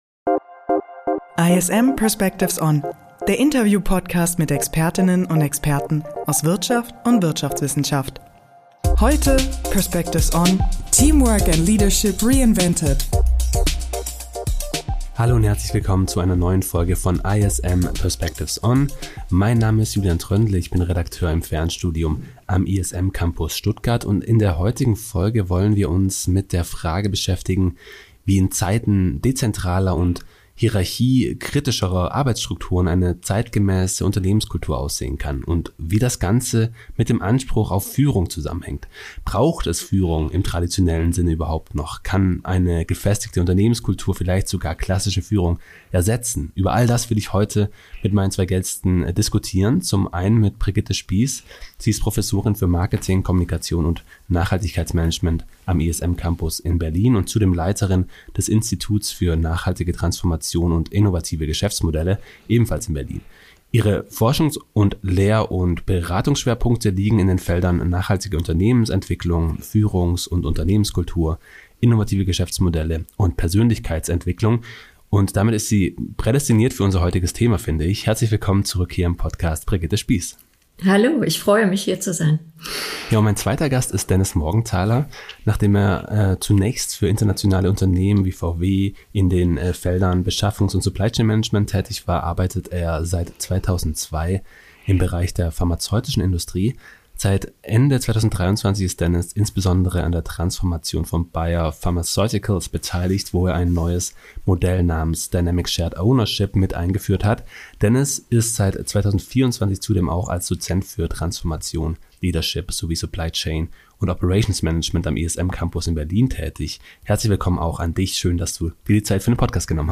Über Einblicke in das Modell "Dynamic Shared Ownership" (DSO) wird ein Gegenkonzept zu klassischen hierarchischen Führungsstrukturen vorgestellt. Ein Gespräch über Vertrauen, Kulturwandel, neue Schlüsselkompetenzen und die Frage, ob Führung bald überflüssig wird – oder gerade jetzt wichtiger denn je ist.